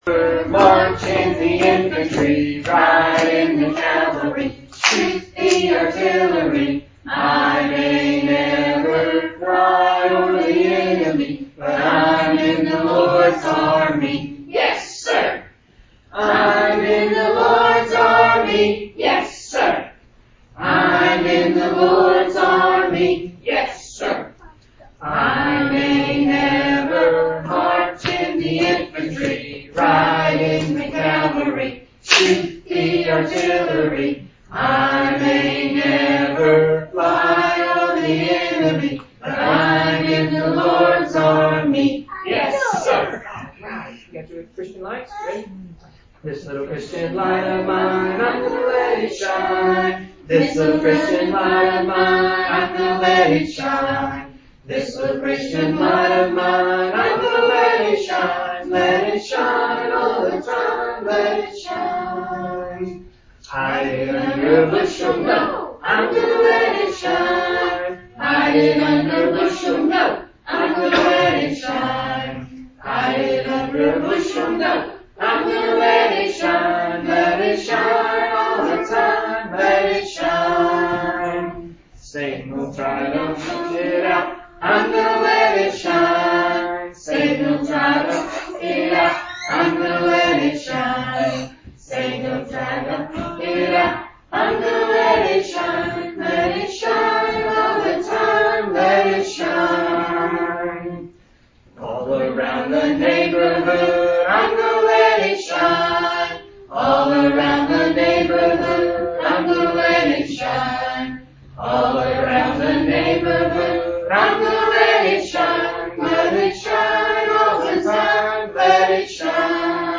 Appears to be a recording of an entire worship service. Note: this service took place in person after Georgia reopened.